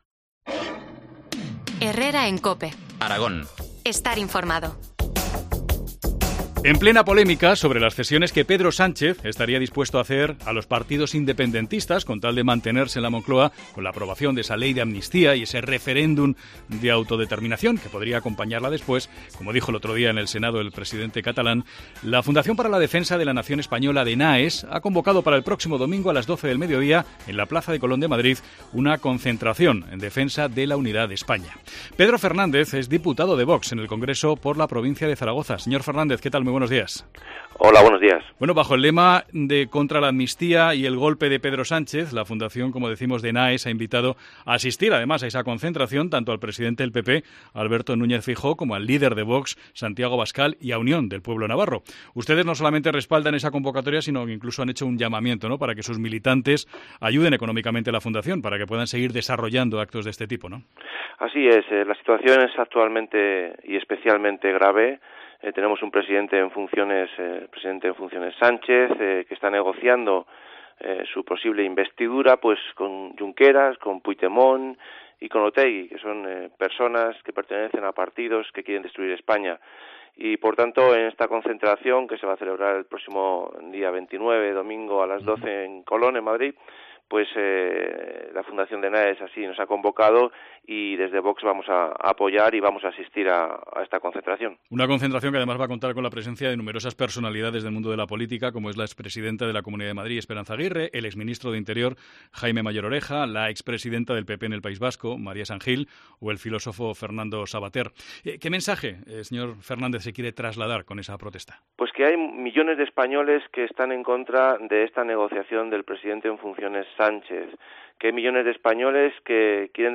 Entrevista al diputado nacional de VOX por Zaragoza, Pedro Fernández.